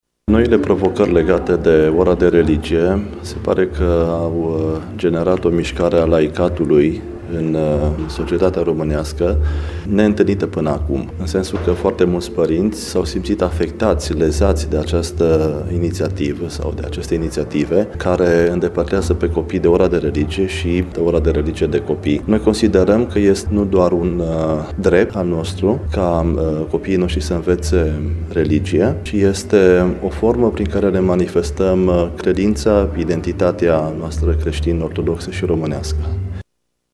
Şedinţa oficială de constituire a filialei locale a avut loc în această după-masă la Sala Ansamblului „Mureşul” din cartierul Dâmbul Pietros.